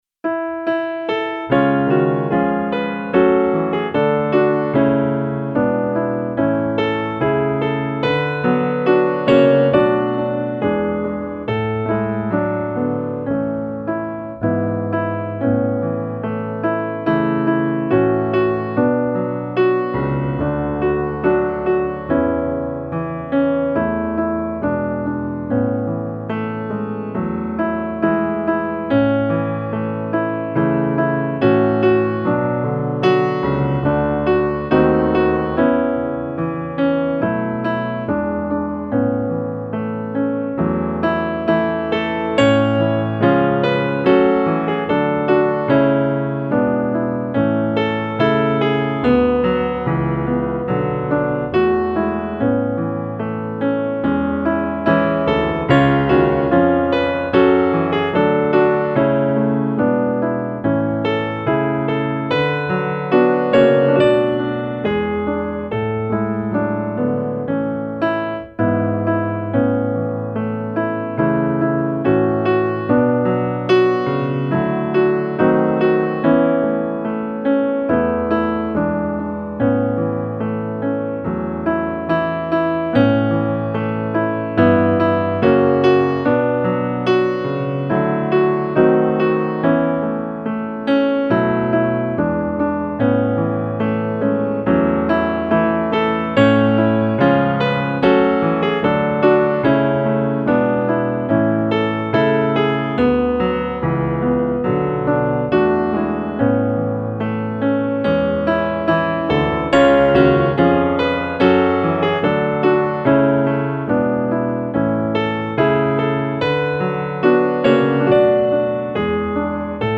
O store Gud, när jag den värld beskådar - musikbakgrund
Gemensam sång
Musikbakgrund Psalm